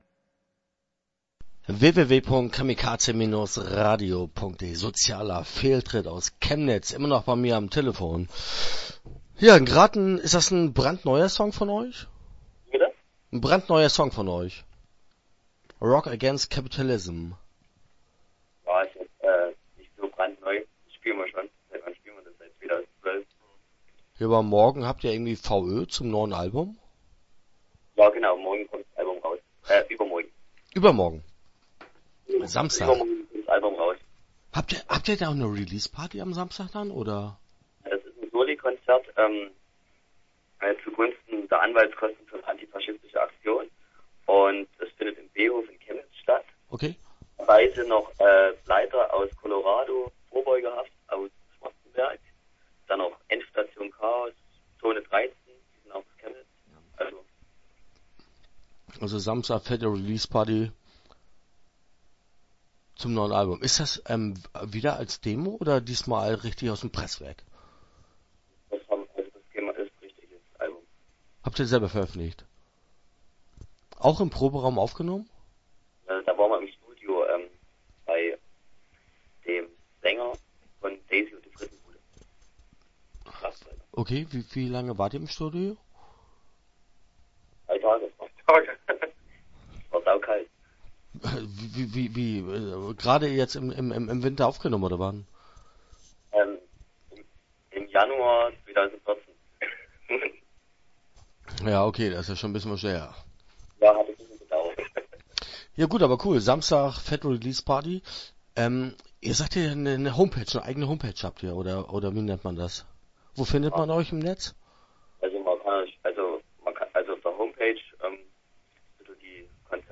Start » Interviews » Sozialer Fehltritt